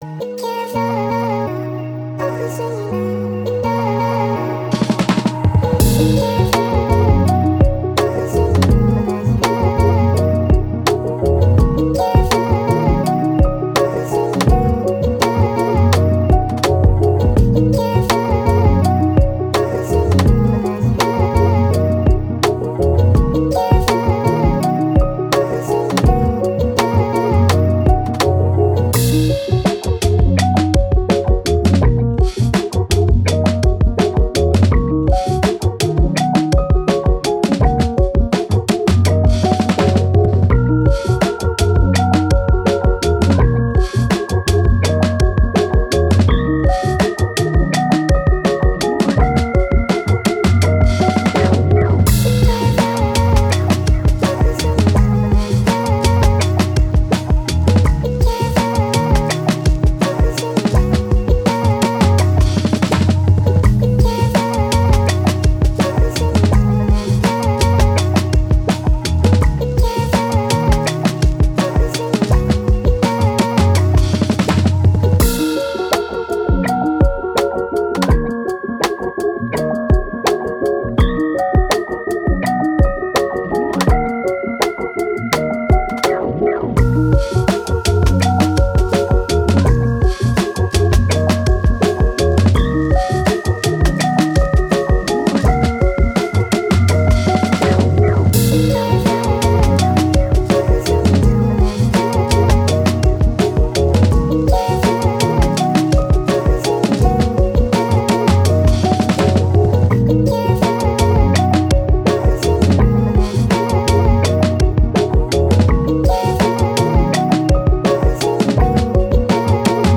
Hip Hop, Thoughtful